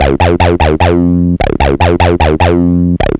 150BEAT3.mp3